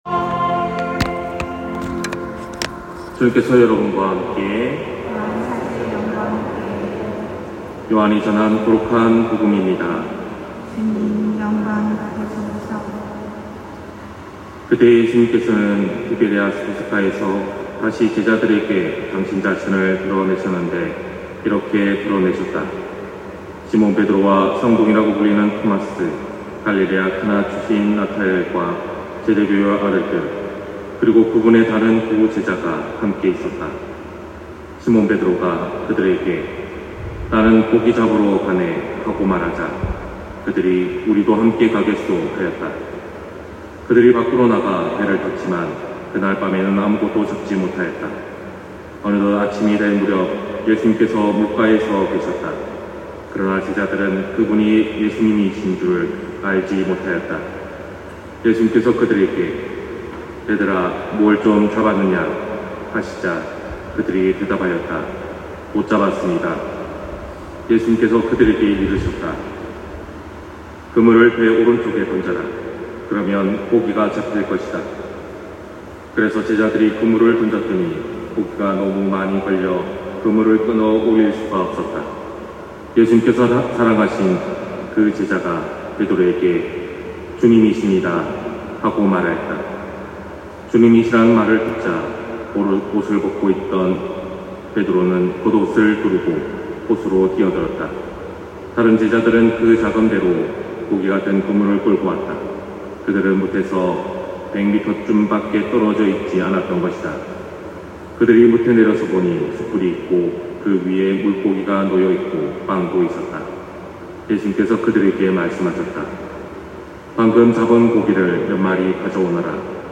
250503 신부님 강론말씀